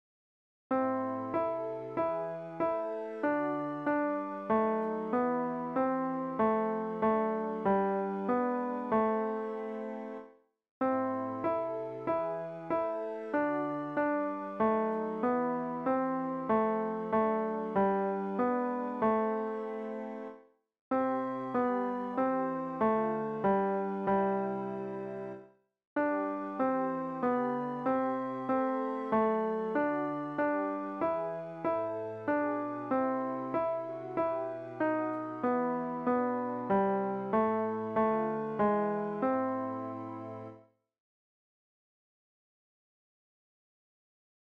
Stimmen - MIDI / mp3 (Chor) mp3